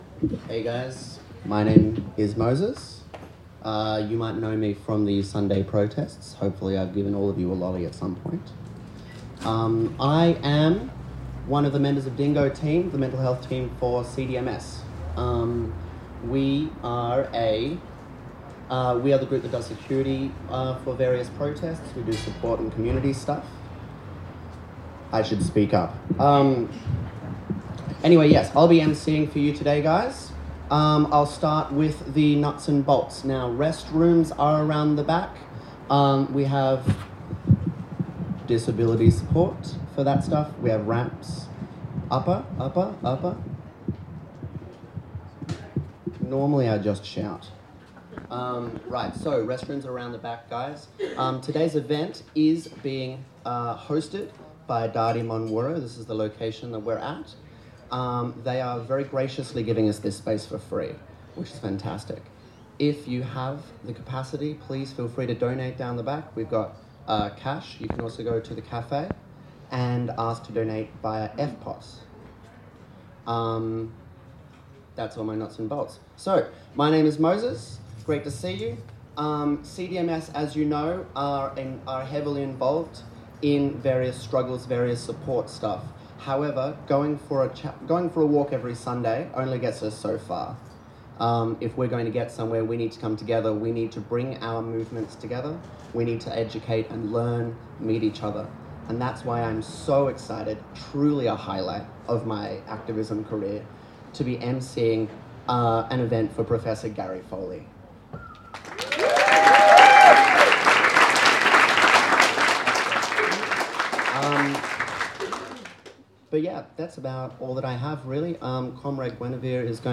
Gary Foley is a Gumbaynggirr activist, artist, historian and writer. You can listen to the full lecture by Uncle Professor Gary Foley here.
Prof Gary Foley full lecture.mp3